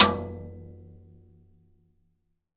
Index of /90_sSampleCDs/AKAI S6000 CD-ROM - Volume 5/Cuba2/TIMBALES_2